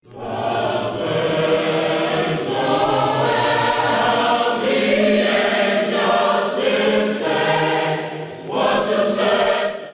Christmas in Cleveland - East High School - Traditional Medley, The First Noel, Silent NightClick on the title to hear a snippet of the song